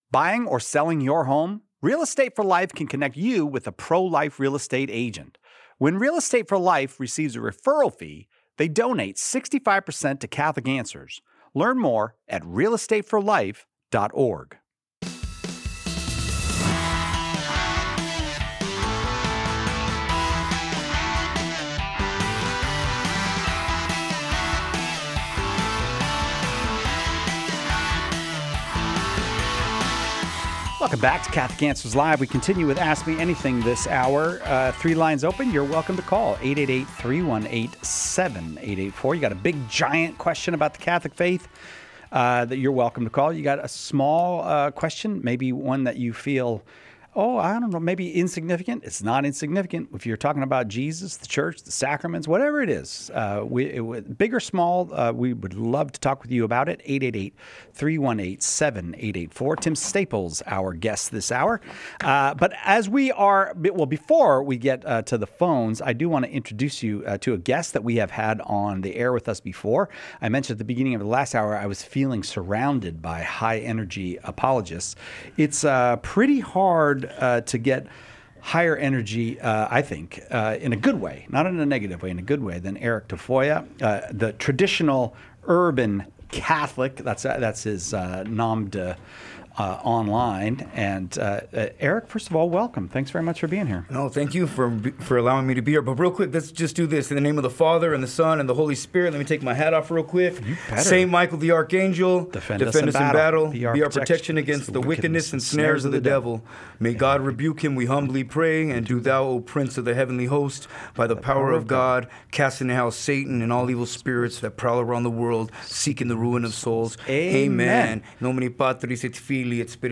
In this episode of Catholic Answers Live, listeners ask deep and thought-provoking questions about Scripture, the sacraments, and God’s love.